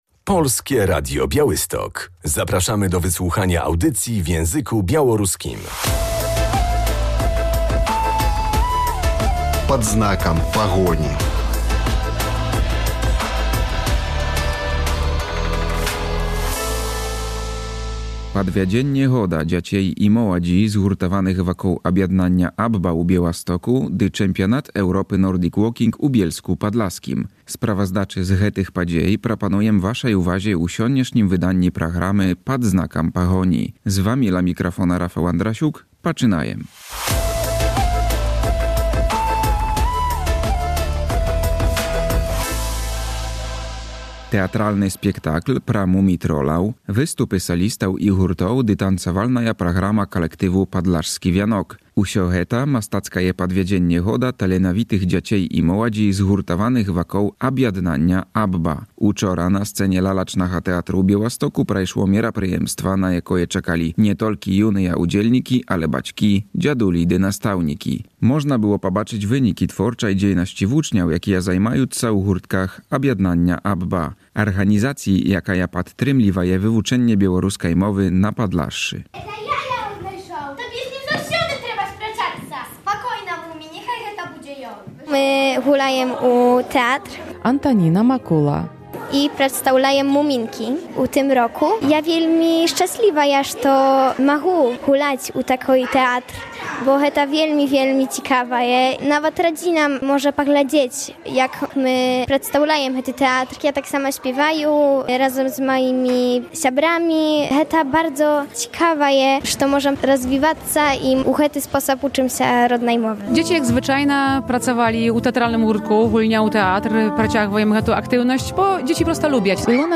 Spektakl teatralny o muminkach, występy solistów i zespołów oraz pokaz taneczny grupy „Podlaski Wianek” – tak wyglądało artystyczne podsumowanie roku szkolnego na scenie Białostockiego Teatru Lalek w wykonaniu dzieci i młodzieży Stowarzyszenia „AB-BA”.